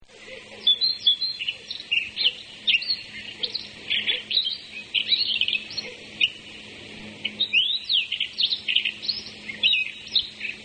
Песня соловья-красношейки
krasnosheika.mp3